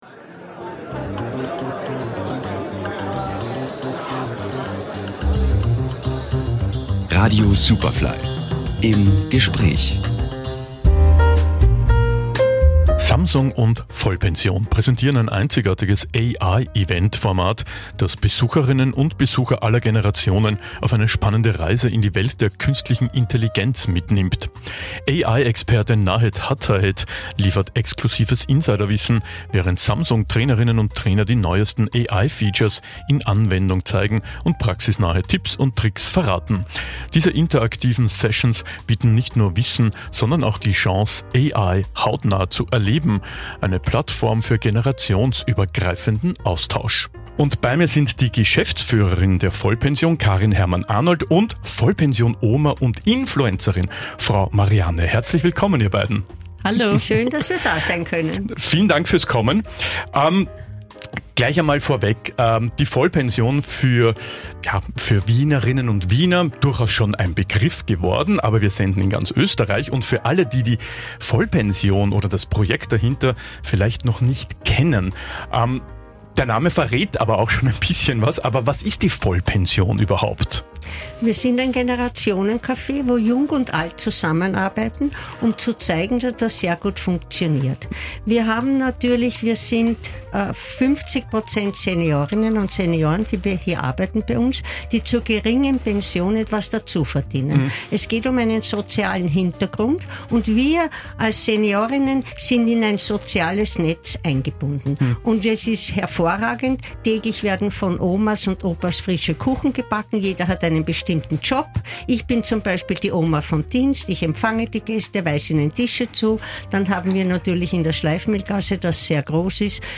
Superfly Featured | Im Gespräch: Vollpension